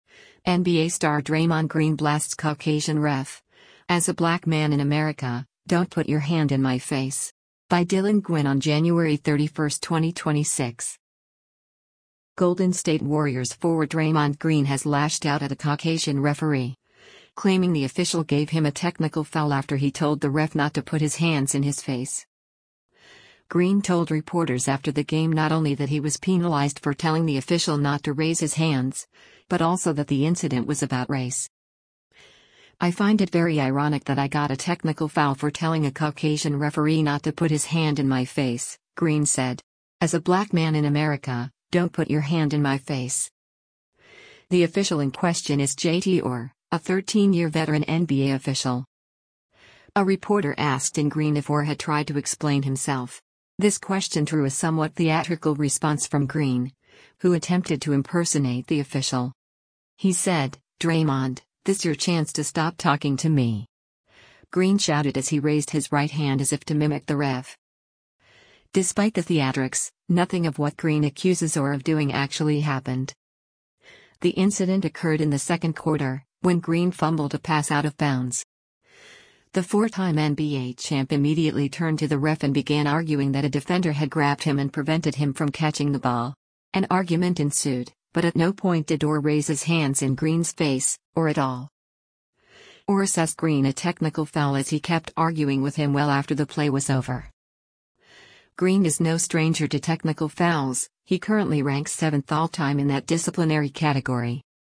Green told reporters after the game not only that he was penalized for telling the official not to raise his hands, but also that the incident was about race.